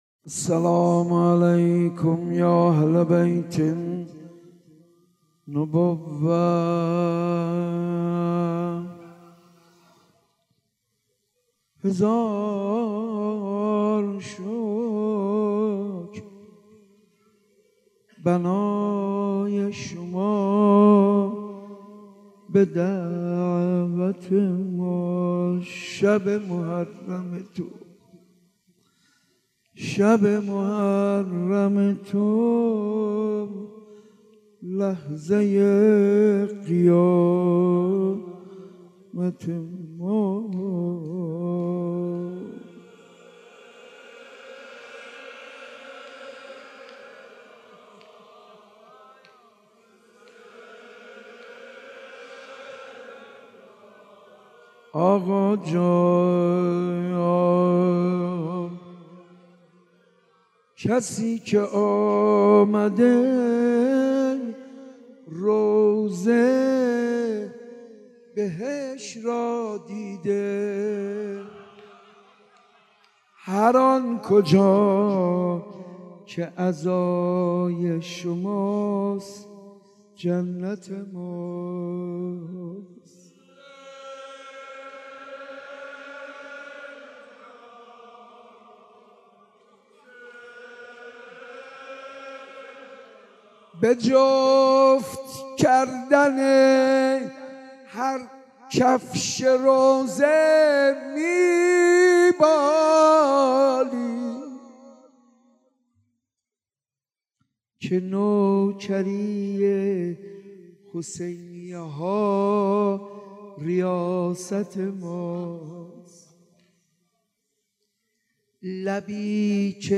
صوت مراسم شب هفتم محرم ۱۴۳۷ مسجد ارگ ذیلاً می‌آید: .:اشکال در بارگذاری پخش کننده:.
حاج منصور ارضی-شب هفتم محرم 1437-گلچین-مسجد ارگ.mp3